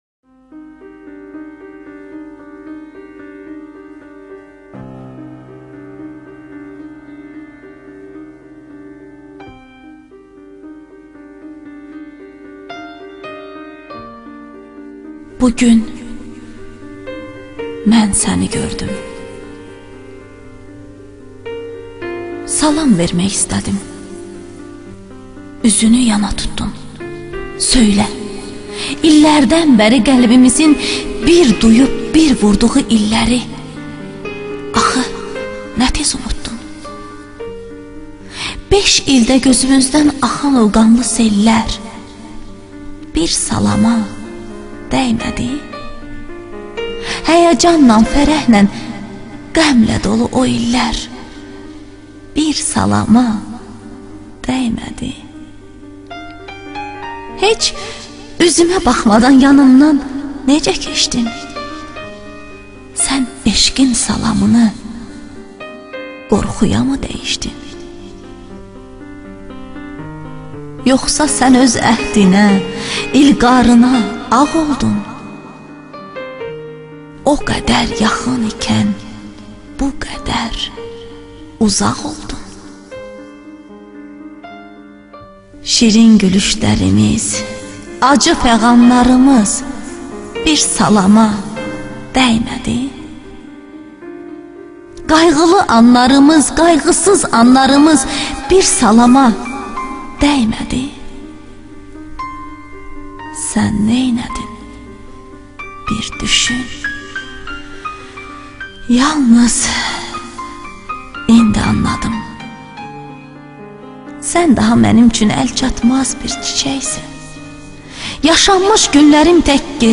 ŞEİRLƏR
AKTYORLARIN İFASINDA